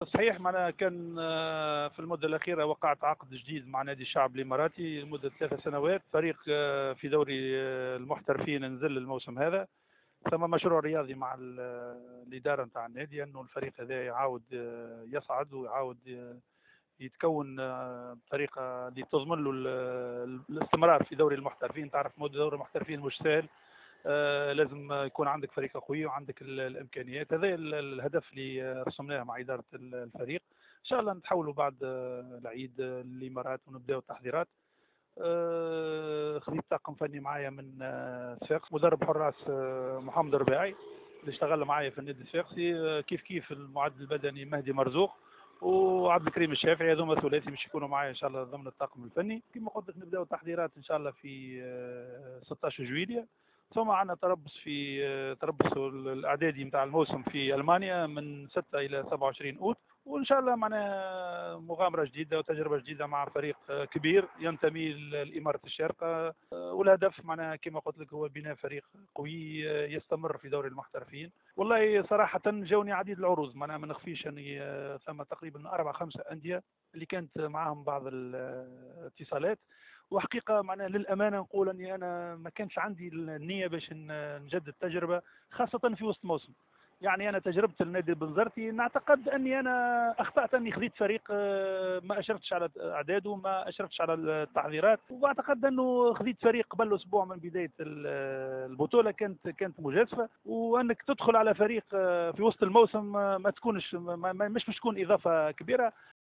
اكد المدرب التونسي غازي الغرايري في تصريح لجوهرة اف ام انه سيتحول بعد عيد الفطر الى الامارات لبداية تجربته الجديدة مع فريق الشعب الاماراتي .و اختار الغرايري الفريق الاماراتي بالرغم من العروض التونسية مؤكدا انه سيعمل على اعادة الفريق الى دوري المحترفين بعد نزوله الموسم الفارط الى الدرجة الثانية .